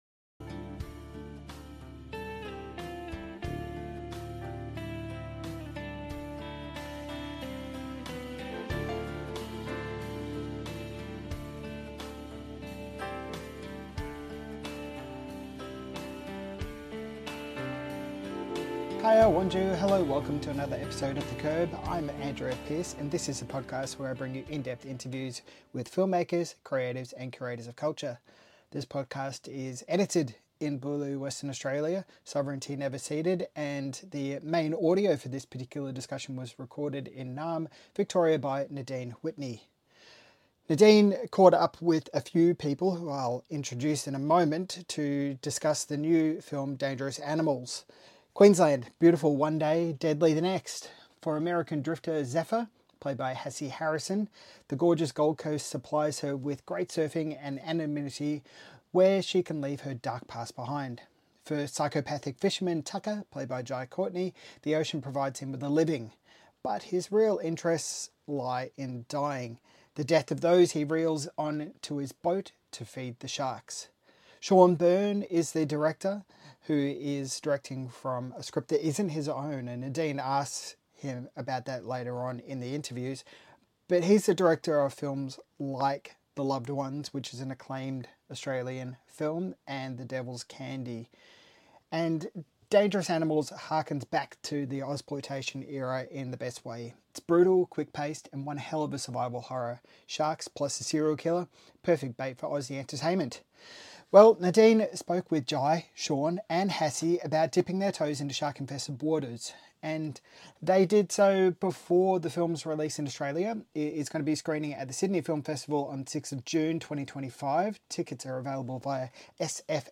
Sydney Film Festival Interview